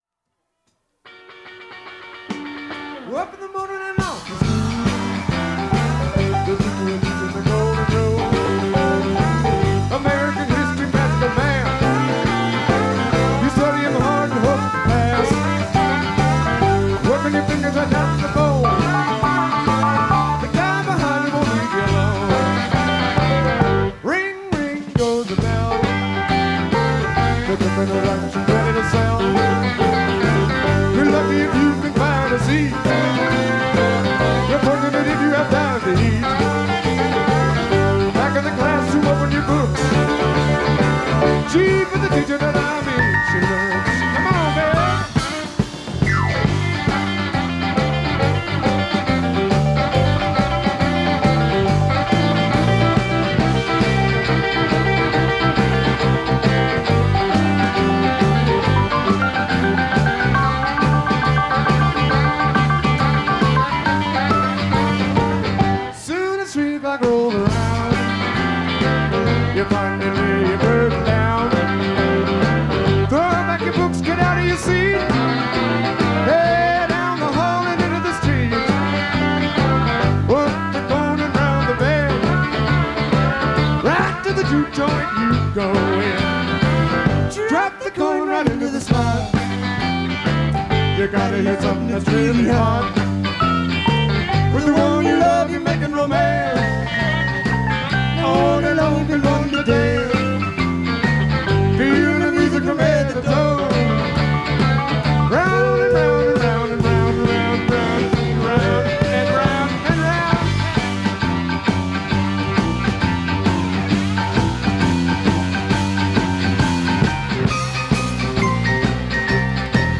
Felt Forum, Madison Square Garden New York City, NY
rhythm guitar
pedal steel guitar
drums